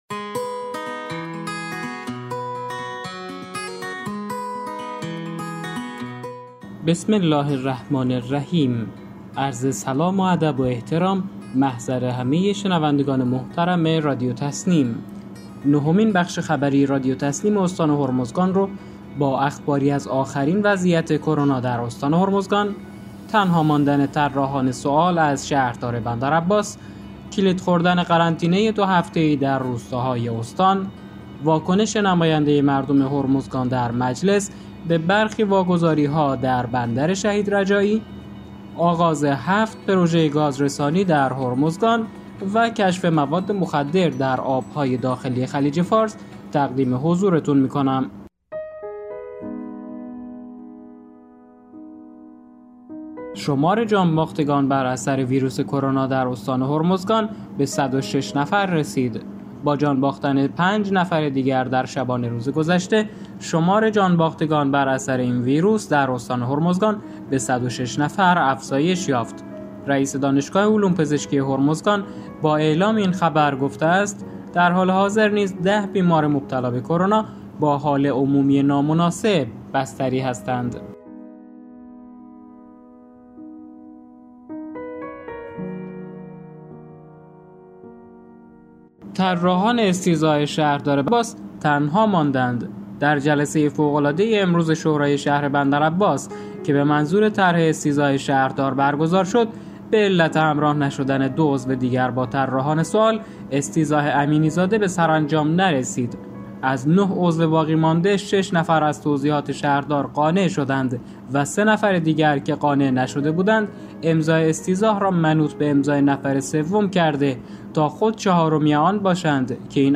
به گزارش خبرگزاری تسنیم از بندرعباس، نهمین بخش خبری رادیو تسنیم استان هرمزگان با اخباری آخرین وضعیت کرونا در استان هرمزگان، تنها ماندن طراحان سوال از شهردار بندرعباس، کلید خوردن قرنطینه 2 هفته ای در روستاهای استان، واکنش نماینده مردم هرمزگان در مجلس به برخی واگذاری‌ها در بندر شهید رجایی، آغاز 7 پروژه گازرسانی در هرمزگان و کشف مواد مخدر در آب‌های داخلی خلیج فارس، منتشر شد.